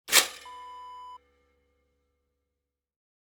Toaster lever up sound effect .wav #2
Description: The sound of a toaster lever popping up
Properties: 48.000 kHz 24-bit Stereo
A beep sound is embedded in the audio preview file but it is not present in the high resolution downloadable wav file.
Keywords: toaster, lever, toast, bread, pop, popping, up
toaster-lever-up-preview-2.mp3